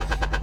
NEW MAGIC WAND Transition.wav